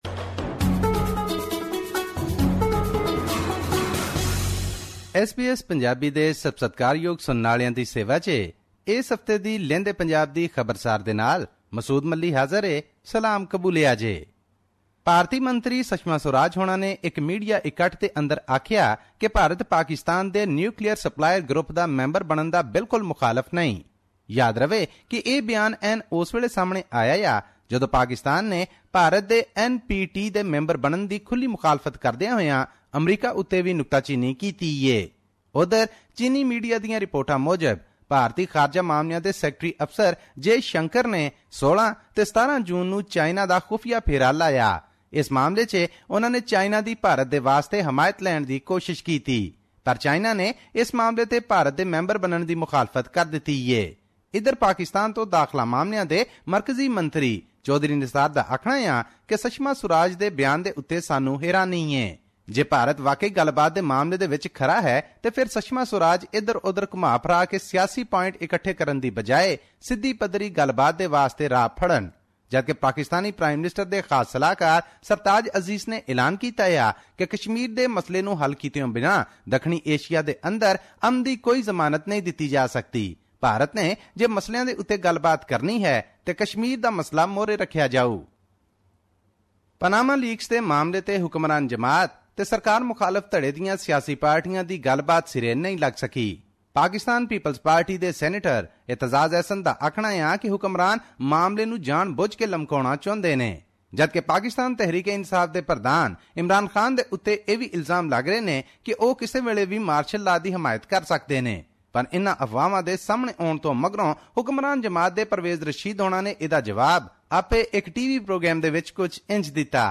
His report was presented on SBS Punjabi program on Tuesday, June 21, 2016, which touched upon issues of Punjab and national significance in Pakistan.